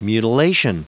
Prononciation du mot mutilation en anglais (fichier audio)